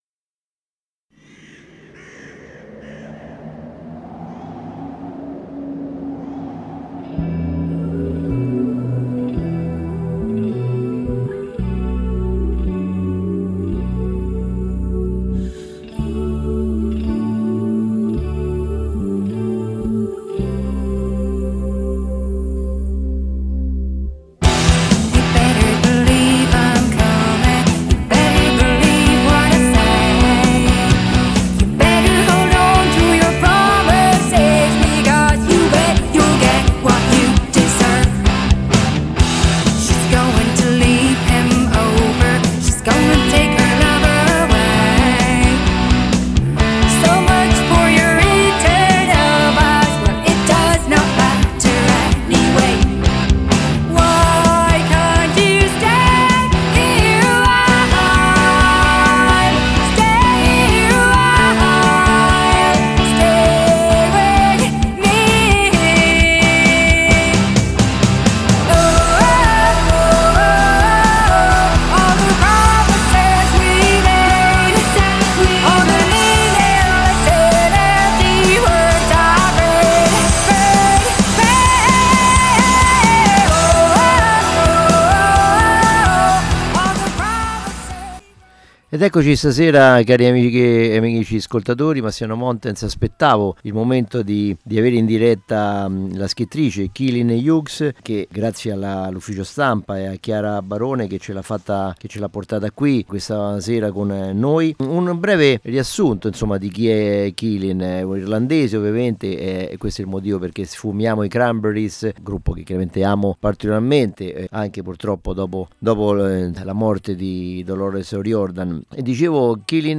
Leggi il suo libro ( dove incontri anche aneddoti forti e sarcastici) e poi quando ascolti la sua voce non ti rendi conto come quel timbro così dolce possa tradursi in un romanzo a volte anche oscuro, duro per il suo realismo.